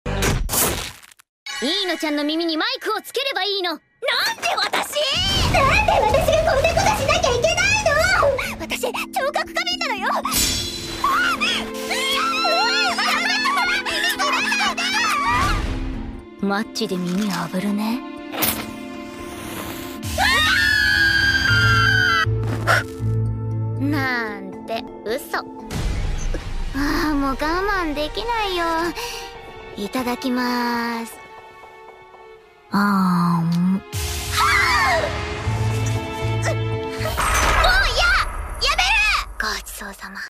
Girls record ASMR and get sound effects free download